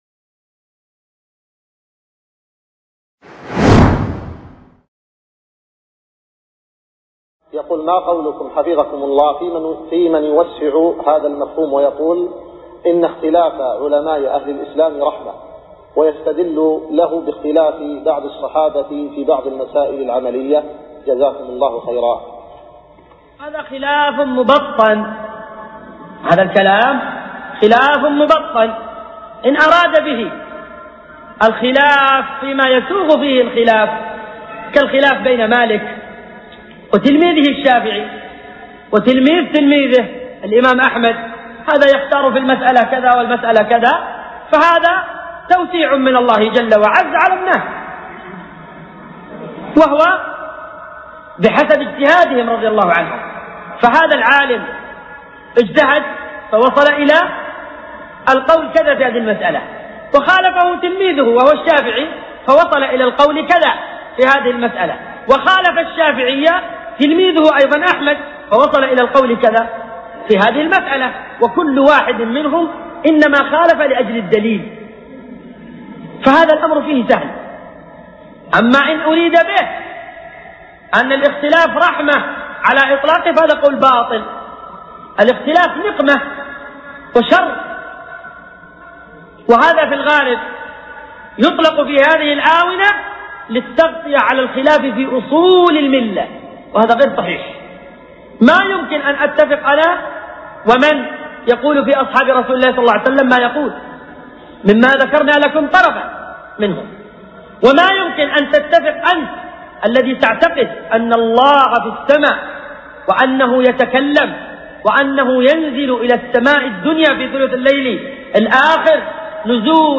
القسم: من مواعظ أهل العلم
Format: MP3 Mono 22kHz 64Kbps (CBR)